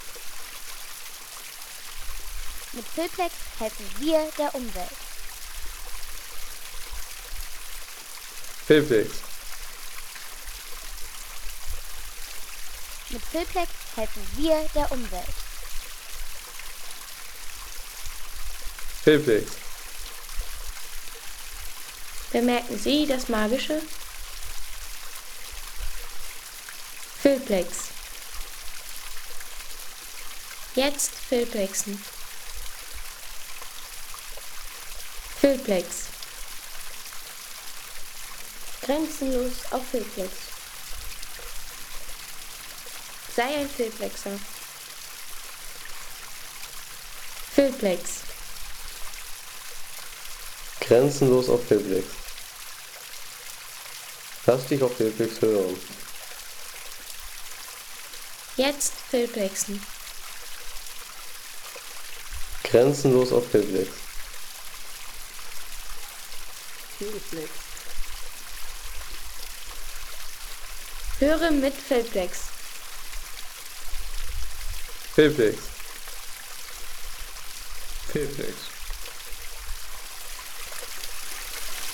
Wasserfall auf Montorfano
Landschaft - Bäche/Seen